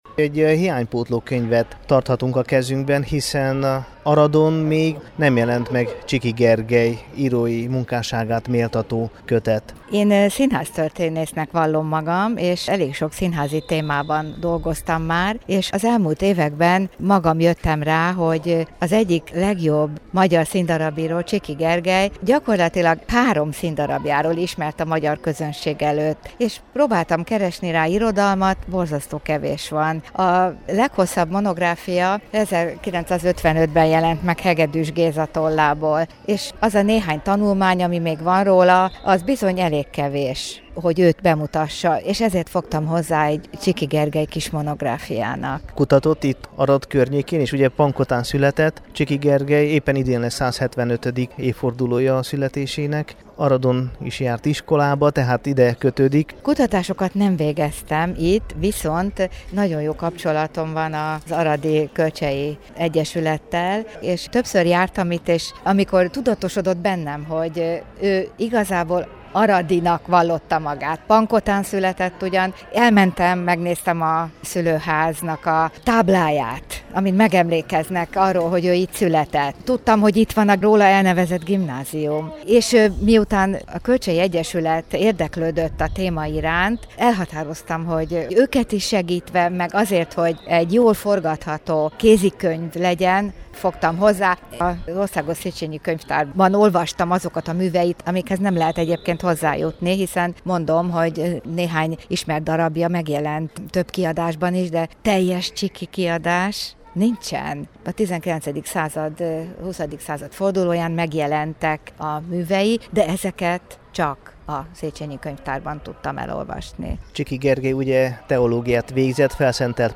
Hallgassa meg a Temesvári Rádió Magyar adása és a Kossuth Rádió Határok nélkül című műsora számára készült interjút: